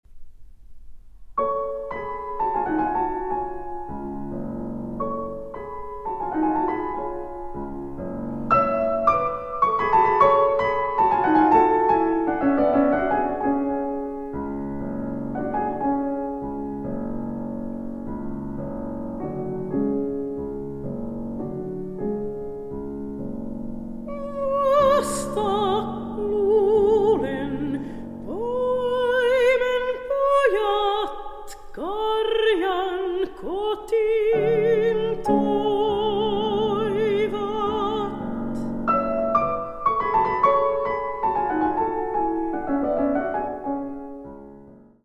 soprano
piano